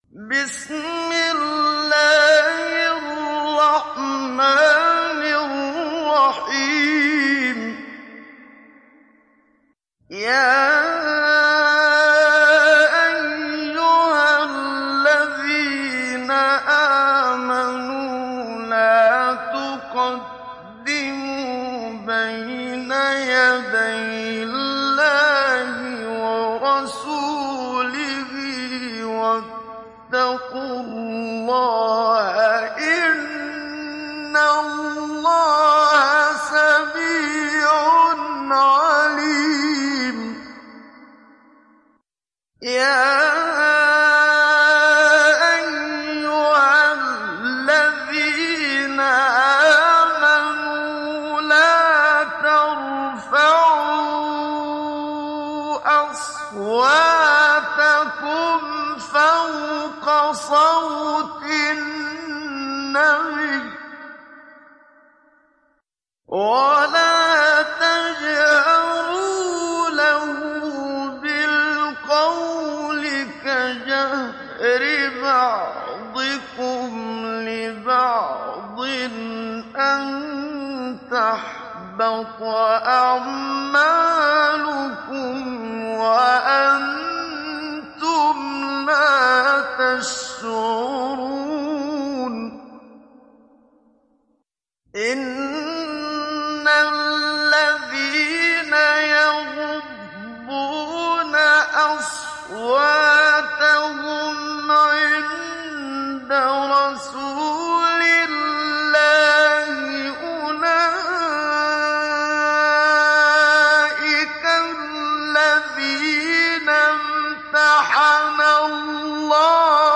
تحميل سورة الحجرات mp3 بصوت محمد صديق المنشاوي مجود برواية حفص عن عاصم, تحميل استماع القرآن الكريم على الجوال mp3 كاملا بروابط مباشرة وسريعة
تحميل سورة الحجرات محمد صديق المنشاوي مجود